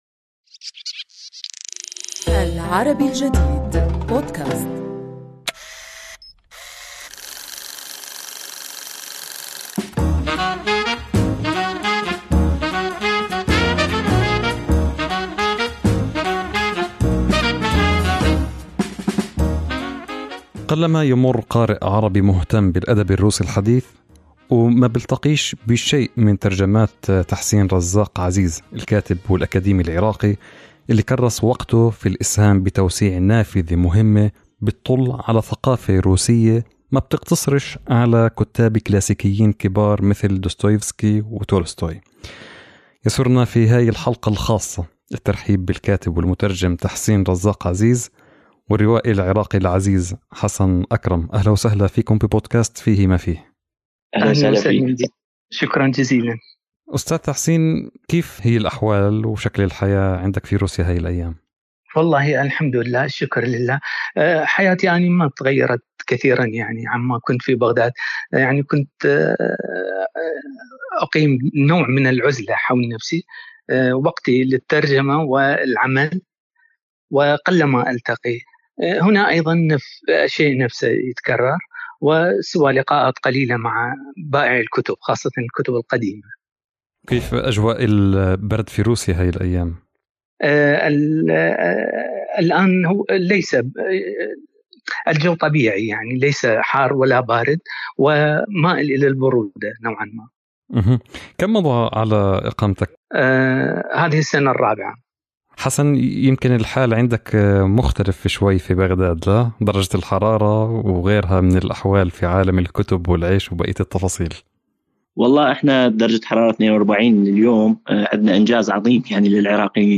في حوار معه